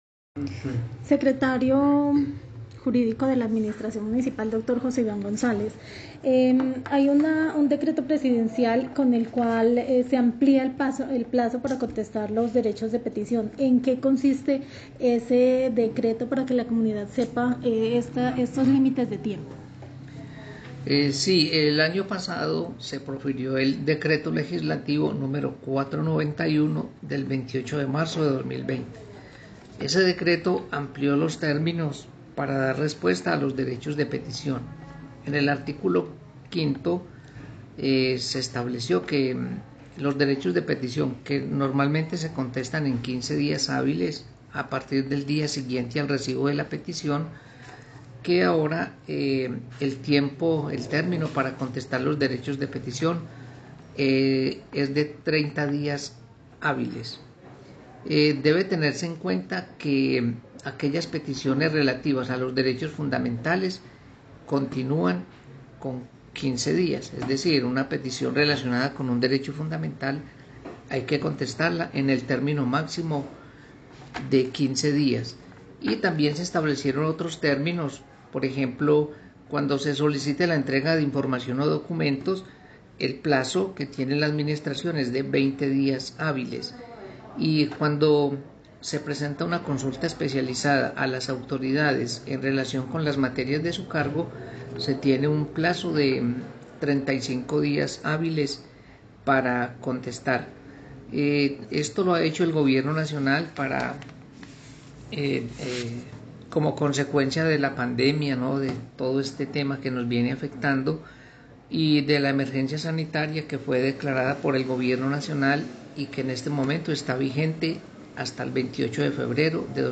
Así lo explicó el Secretario Jurídico de la Administración Municipal de Dosquebradas, José Iván González Arias, quien agregó que esta ampliación de términos aplica para las peticiones generales, con algunas excepciones:
Comunicado-016-Audio-Secretario-Juridico-de-Dosquebradas-Ivan-Gonzalez.mp3